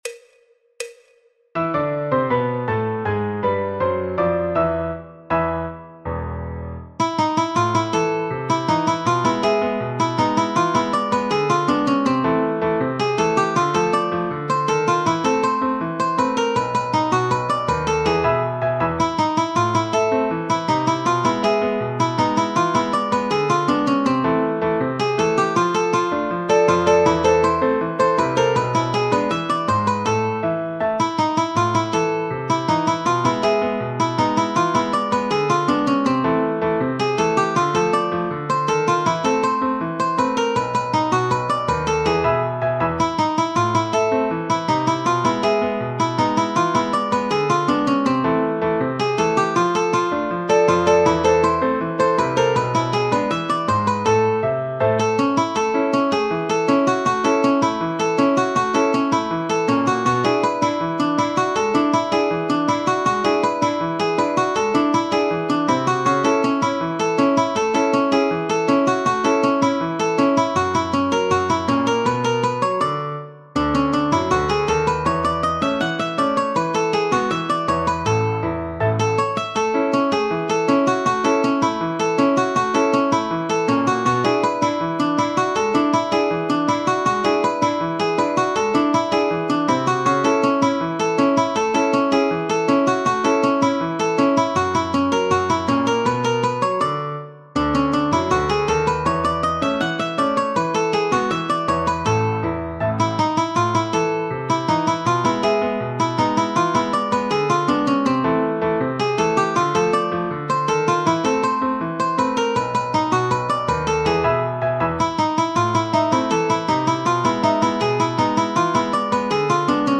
Choro, Jazz, Popular/Tradicional